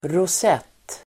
Uttal: [ros'et:]